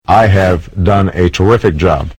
Download Barack Obama Terrific Job sound effect for free.